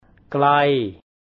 There are five different tones in Thai and a simple error on the pronunciation of one tone can completely alter the meaning of the word.